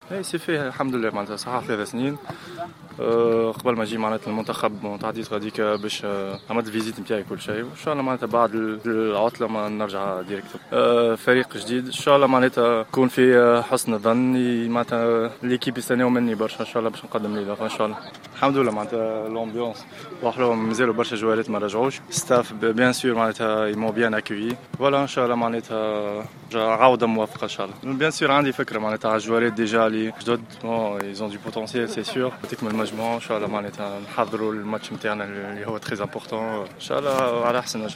تحدث اللاعب حمدي الحرباوي ، الذي إنضم إلى تربص المنتخب التونسي في المنستير للإستعداد لمباراة جيبوتي لحساب تصفيات كأس إفريقيا للأمم الغابون 2017 ، في تصريح لجوهرة أف أم عن التجربة الجديدة التي سيخوضها مع فريق أودينيزي الذي ينشط بالدرجة الأولى الإيطالية إضافة إلى عودته إلى قائمة المنتخب بعد غياب بسبب بعض الخلافات مع الجامعة التونسية لكرة القدم.